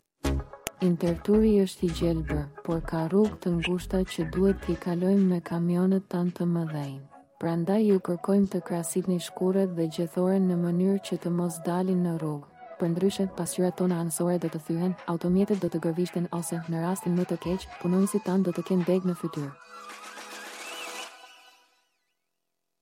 Tipps und Tricks albanisch (made with AI) 23rd January 2026 • Trittbrett-Talk - Abfall-Geschichten aus Winterthur • Stadt Winterthur